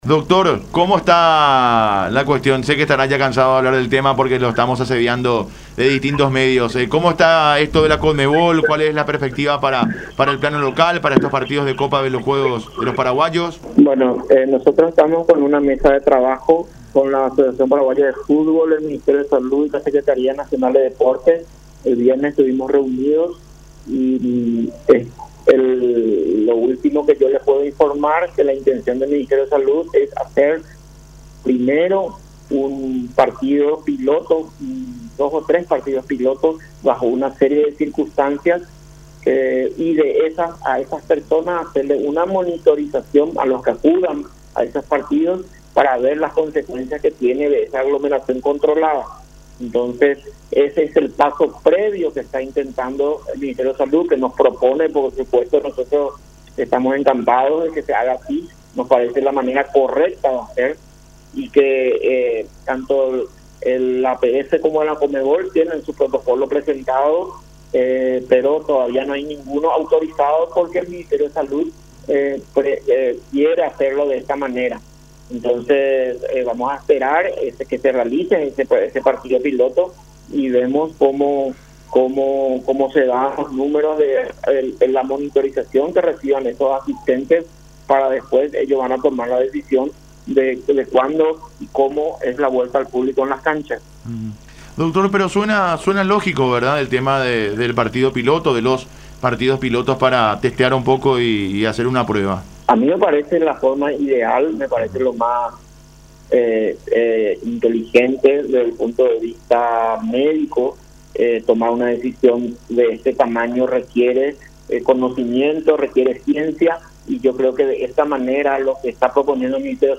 en diálogo con Unión Fútbol Club